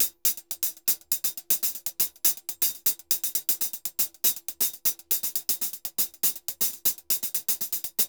HH_Salsa 120_2.wav